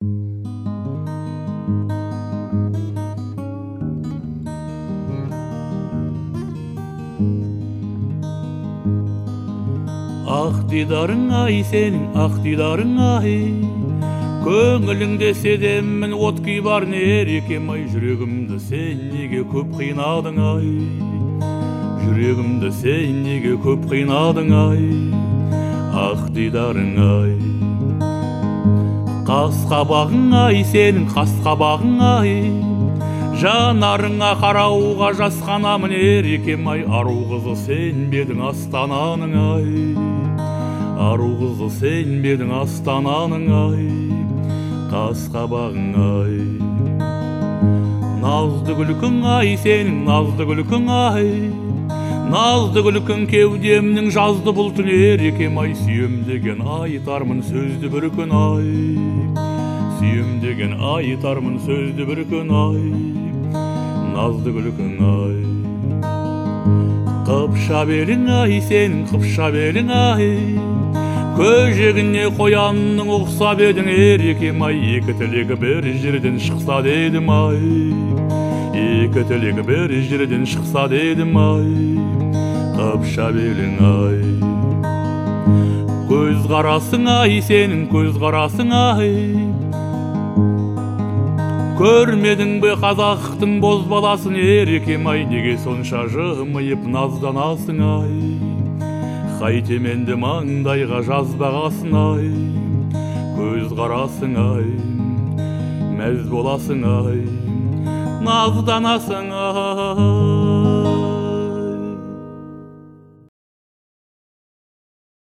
это песня в жанре казахской эстрадной музыки